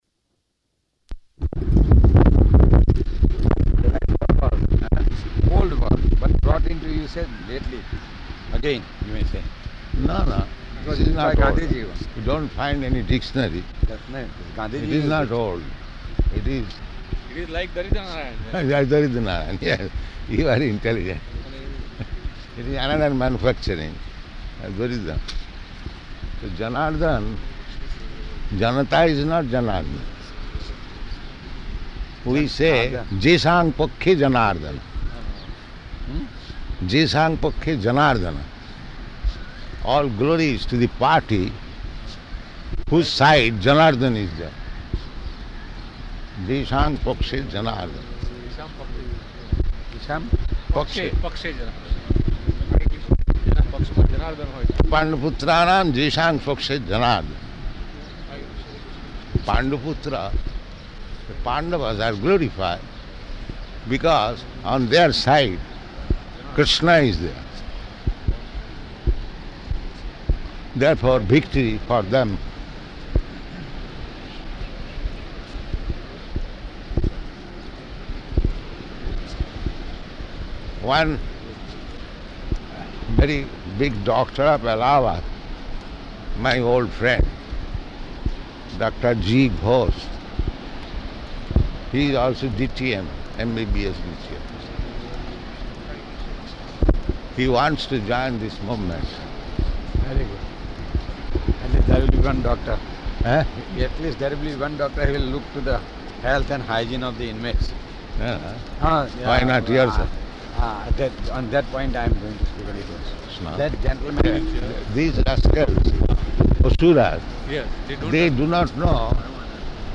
-- Type: Walk Dated: March 29th 1974 Location: Bombay Audio file